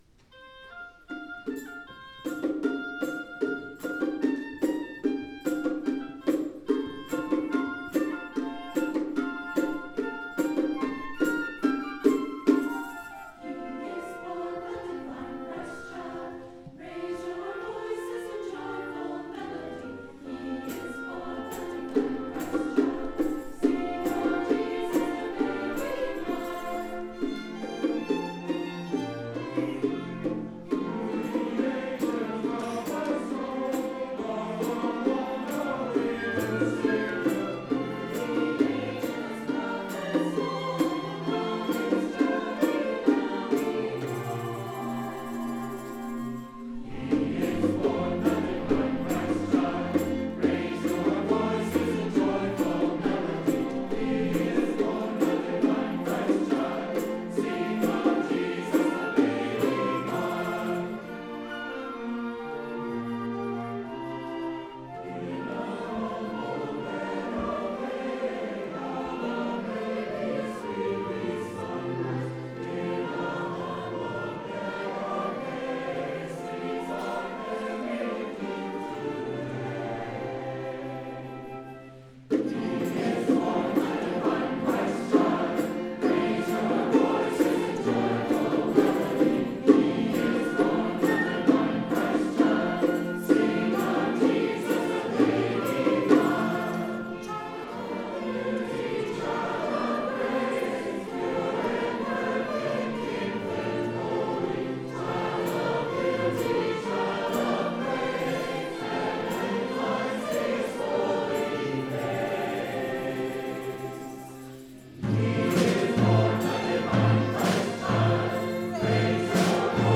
for SATB Choir & Small Orchestra
Live Performance, December 2025:
Composer: Trad. French Carol